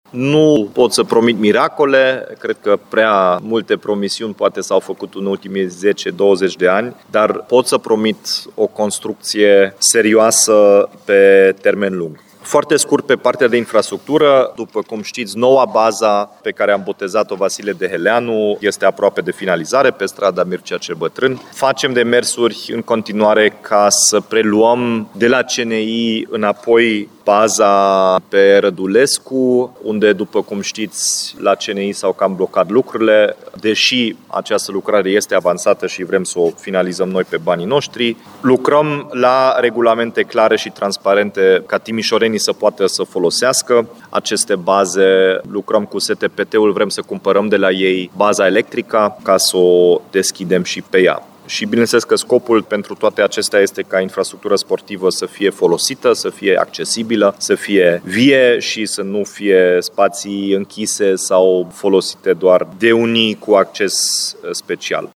La conferința de presă de astăzi, primarul Dominic Fritz a vorbit în primul rând despre infrastructură.